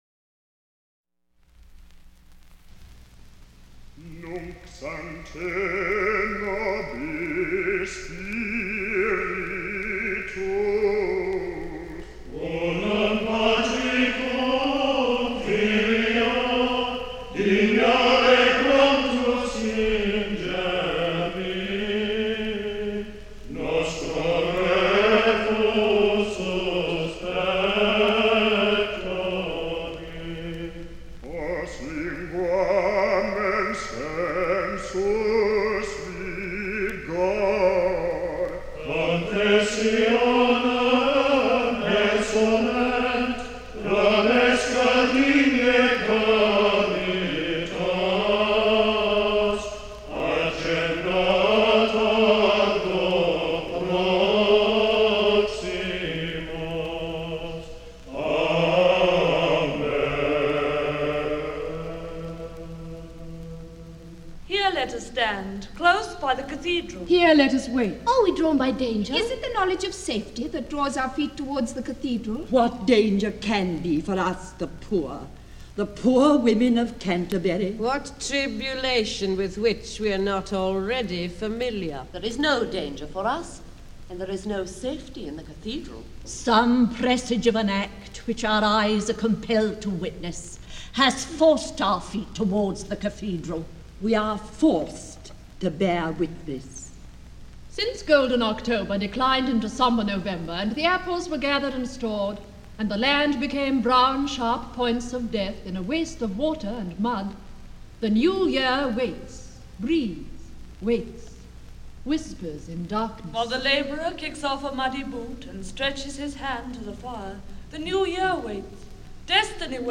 Ukázka z knihy
This re-release of the original 1953 recording stars Robert Donat whose commanding performance as the Archbishop, alongside a full cast, is widely celebrated.